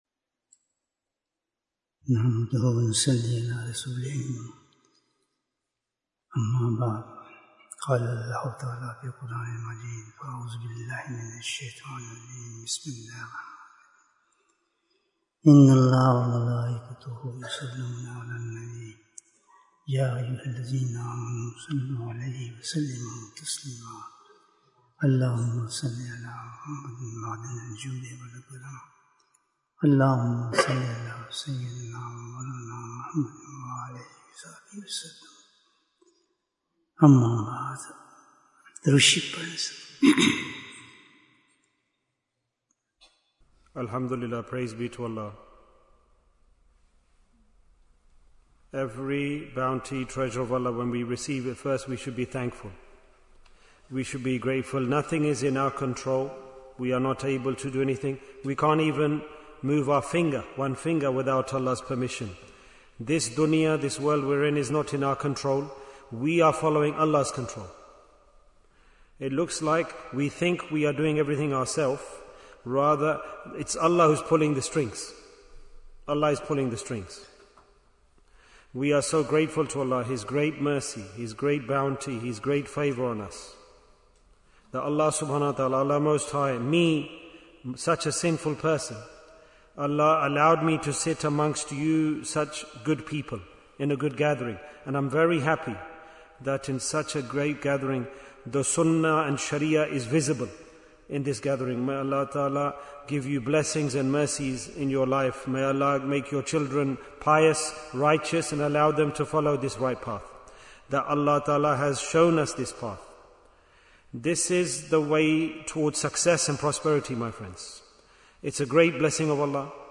Bayan, 91 minutes2nd February, 2026